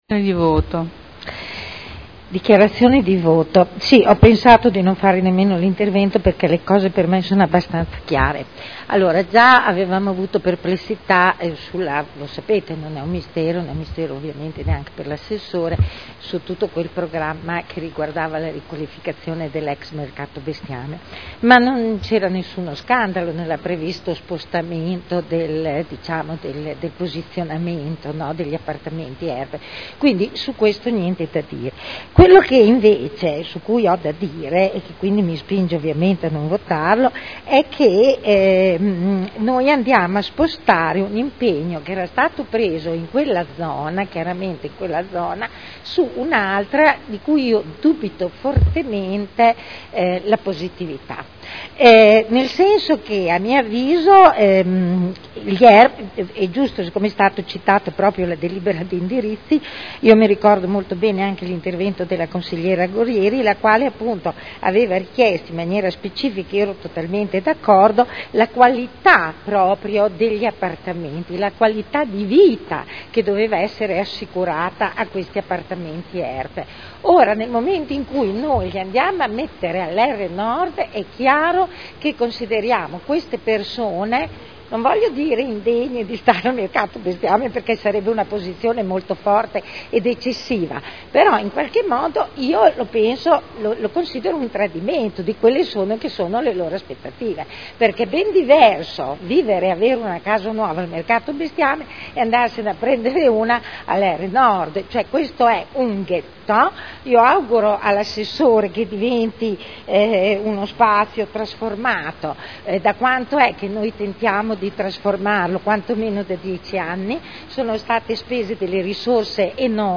Eugenia Rossi — Sito Audio Consiglio Comunale
Proposta di deliberazione: Accordo integrativo al programma per la riqualificazione urbana del comparto “Ex Mercato Bestiame” a Modena (art. 9 della L.R. 19/1998). Dichiarazioni di voto